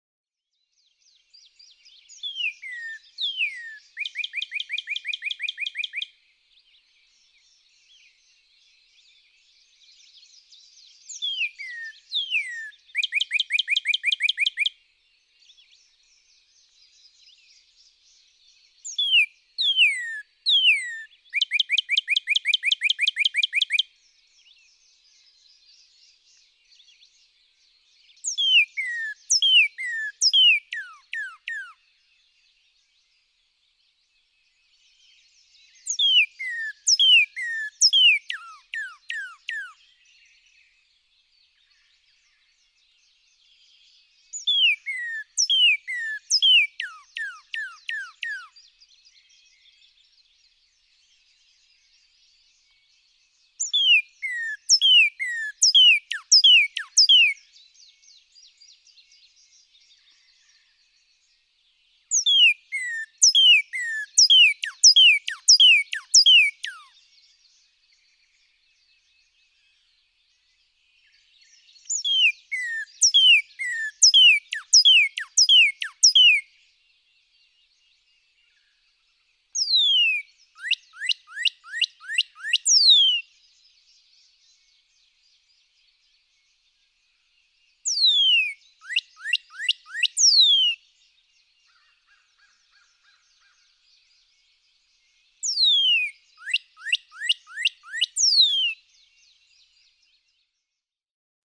Northern cardinal
Three examples for each of four different songs excerpted from a long sequence by a male cardinal (A A A B B B C C C D D D).
Grundy, Virginia.
121_Northern_Cardinal.mp3